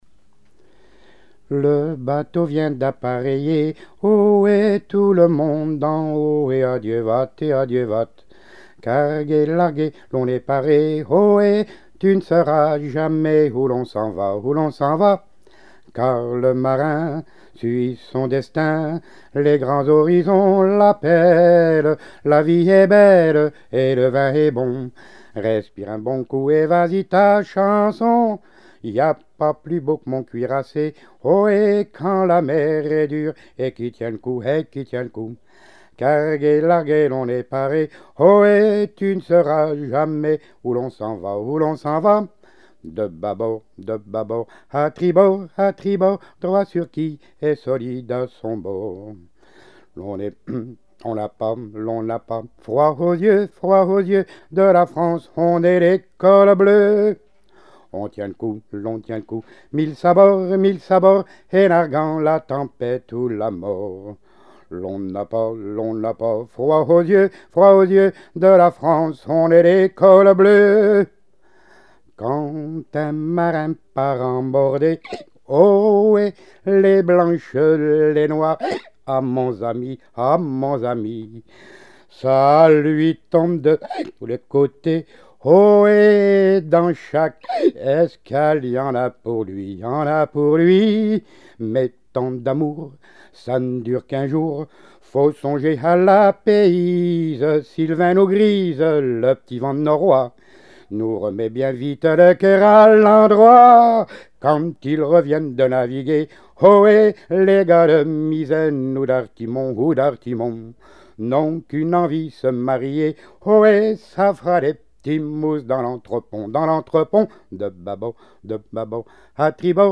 / Tout le monde en haut Texte saisi Non Genre strophique Auteur Paulin (de G.)
Pièce musicale inédite